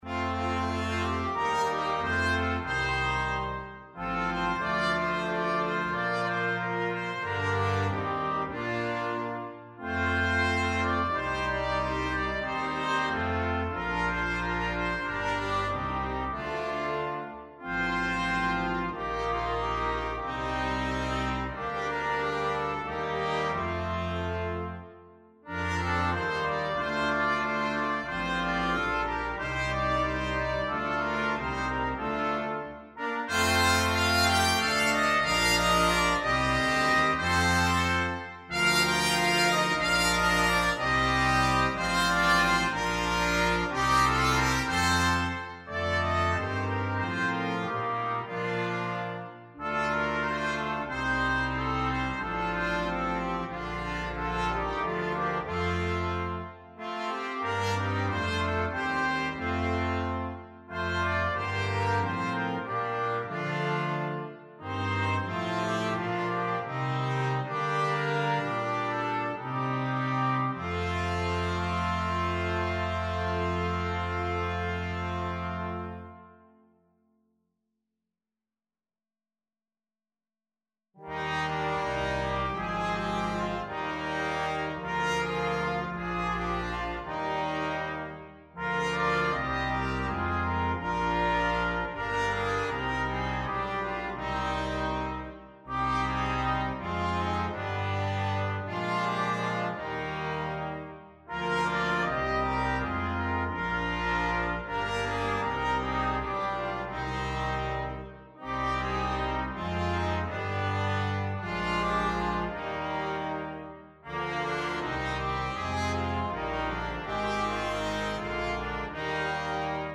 for brass quintet